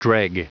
Prononciation du mot dreg en anglais (fichier audio)
Prononciation du mot : dreg